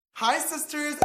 Pop Sound Effect Free Download
Pop